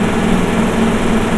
KART_Engine_loop_0.ogg